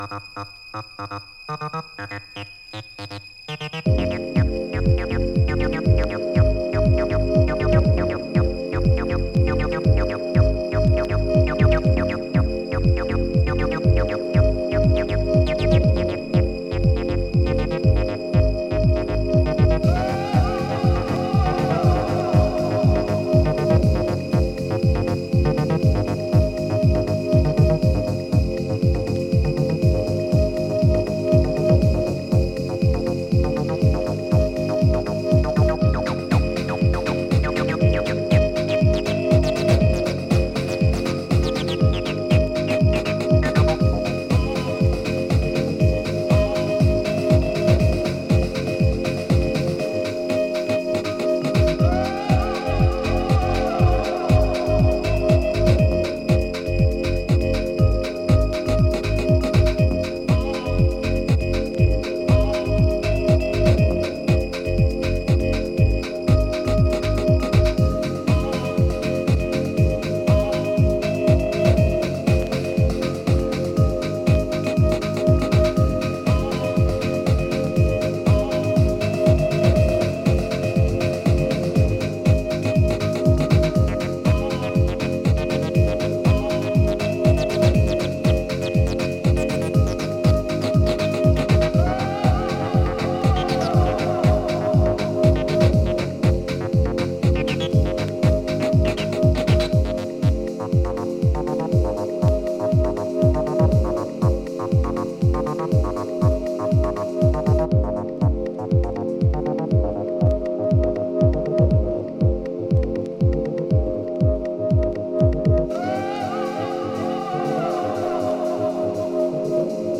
今回もシルキーでメロディアスなシンセワークやアナログマシンのダスティなグルーヴを駆使したディープ・ハウスを展開。